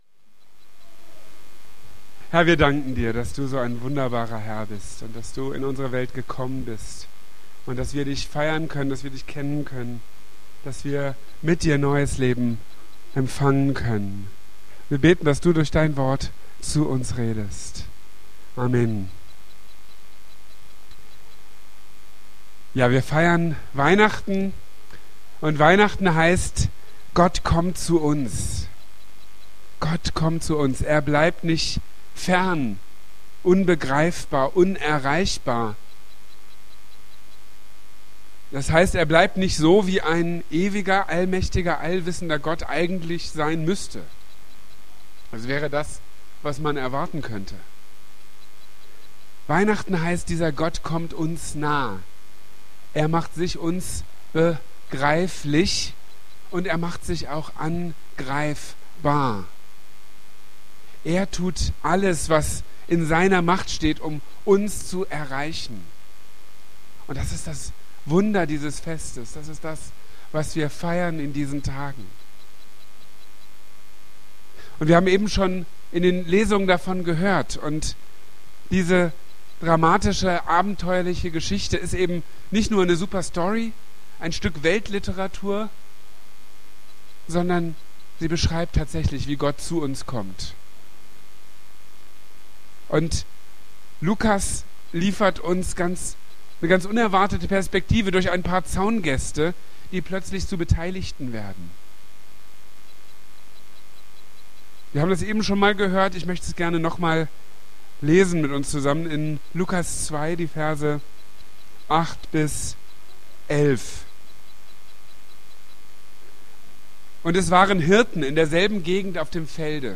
Gott kommt zu uns | Marburger Predigten